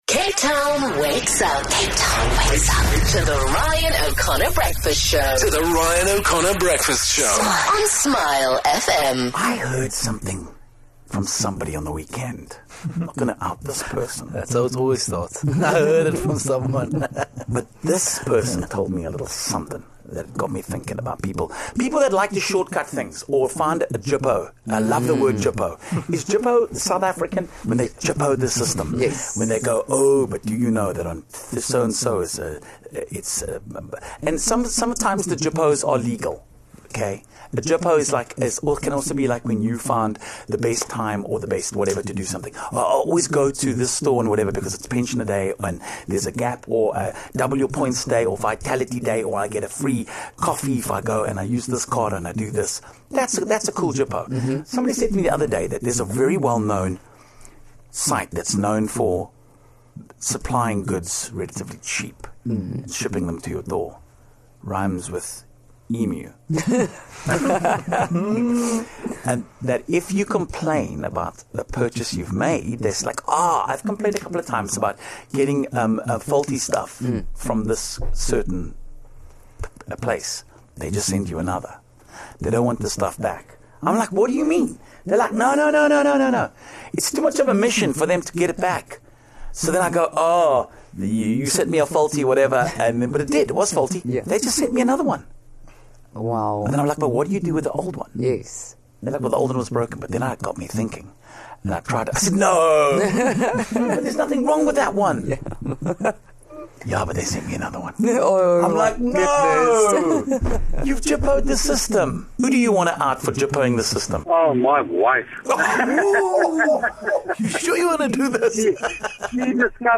Some of listeners have looked for ways to jippo the system somewhere in life. (Disclaimer: we do not condone any actions presented by our callers)